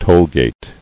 [toll·gate]